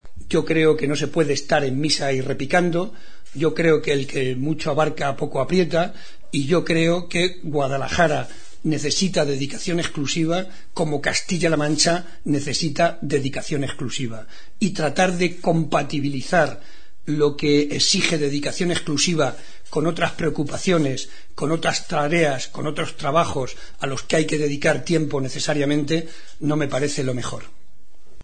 Barreda, que ha recibido hoy el reconocimiento como socio de honor de la Casa de Guadalajara en Madrid, ha explicado en declaraciones a los periodistas que Guadalajara y Castilla-La Mancha necesitan dedicación exclusiva, por lo que tratar de compatibilizar lo que exige dedicación exclusiva con otras preocupaciones y trabajos a los que hay que dedicar tiempo no le parece adecuado.
Cortes de audio de la rueda de prensa